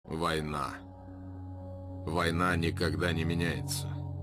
мужской голос
голосовые